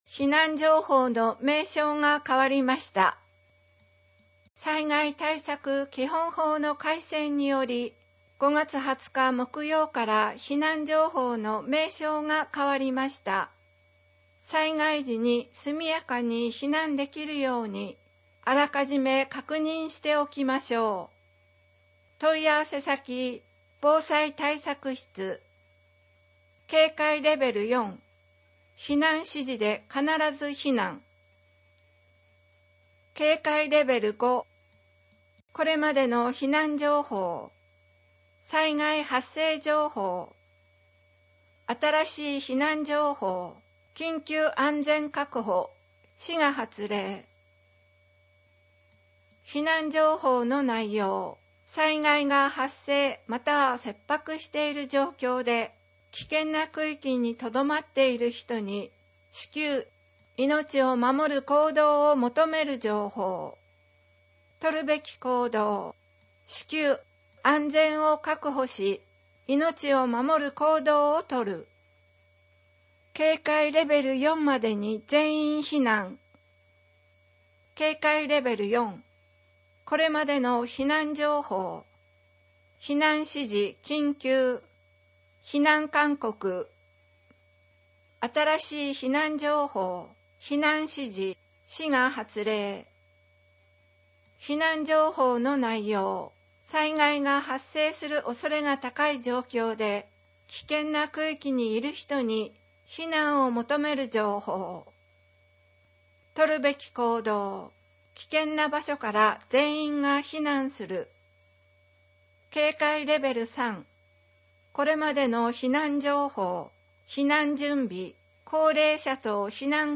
声の広報MP3版は、岩見沢さつきの会にご協力をいただき、録音しているものです。
声の広報（MP3）